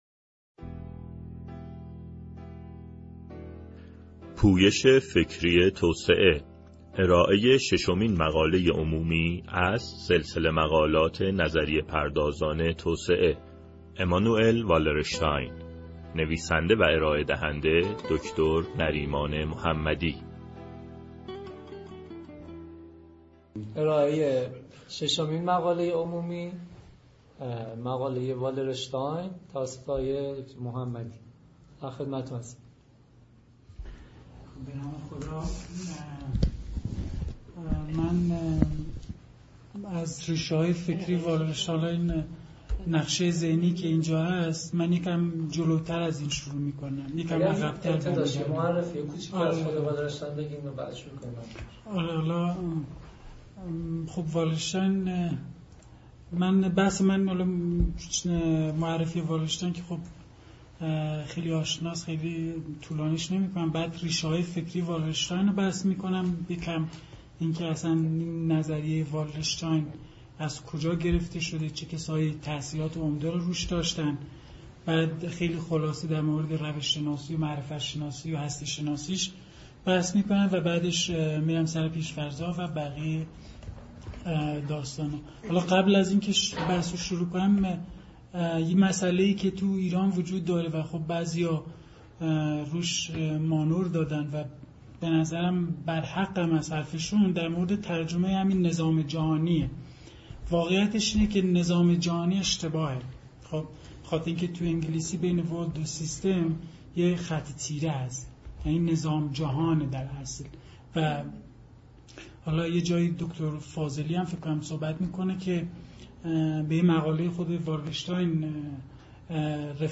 ششمین جلسه ارائه مقالات عمومی